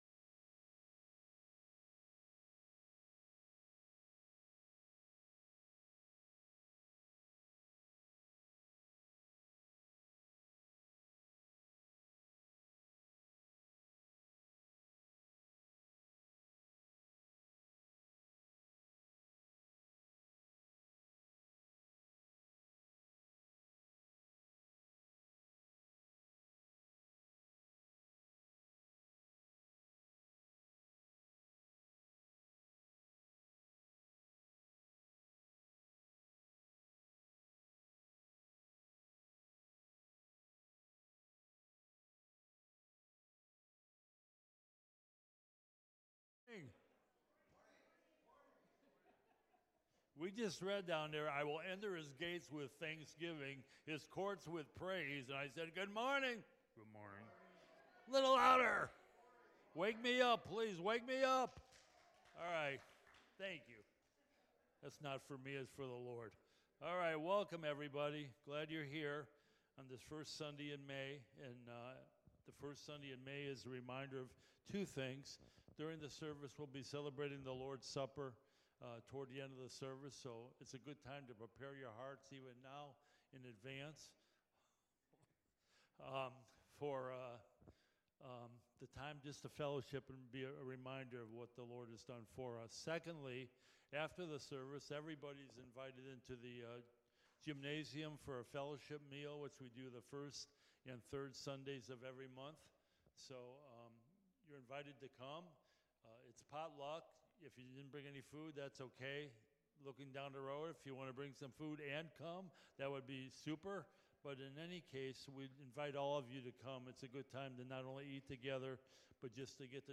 Sermons | Scranton Road Bible Church